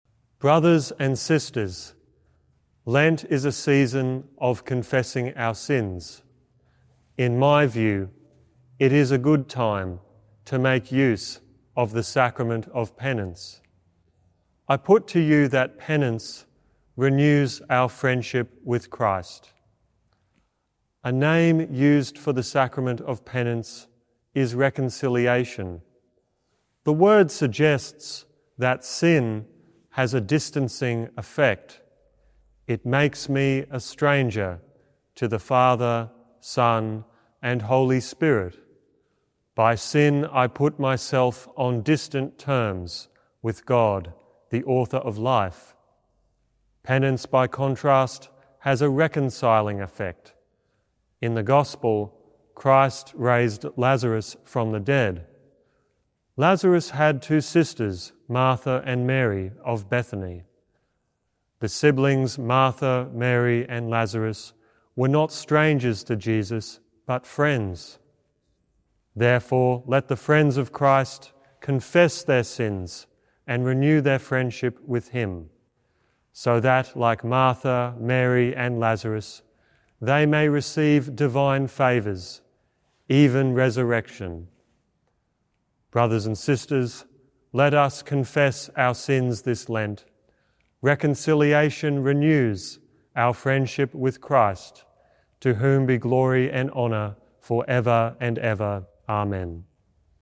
Archdiocese of Brisbane Fifth Sunday of Lent - Two-Minute Homily